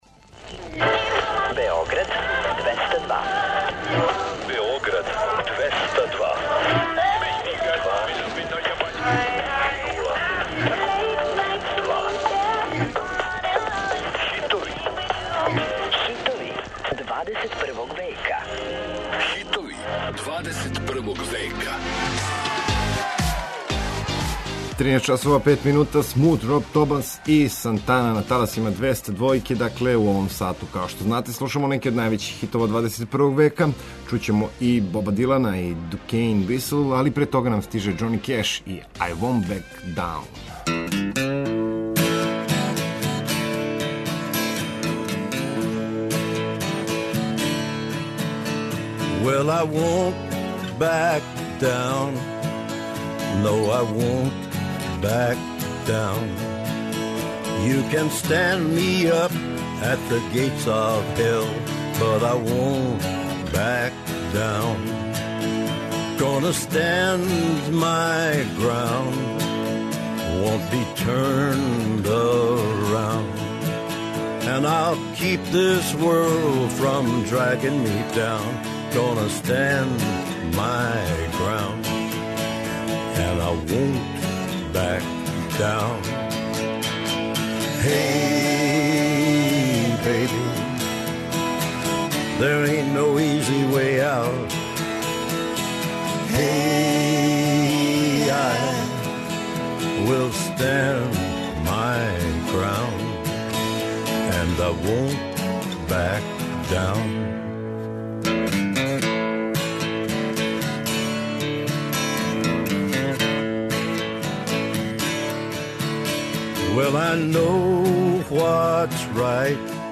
Сваког радног дана, у термину 13-14 сати, подсећамо вас на хитове новог миленијума. Песме које можете да слушате су, од 2000. године до данас, биле хитови недеље Београда 202, или су се налазиле на првим местима подлиста новитета Топ листе 202.